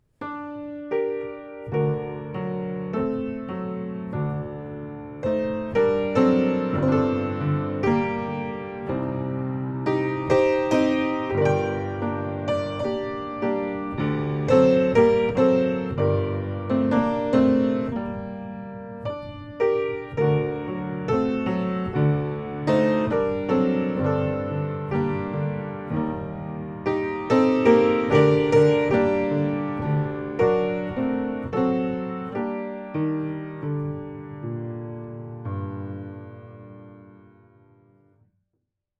Notes: for piano
Dramatic Andante. Pathetic or Plaintive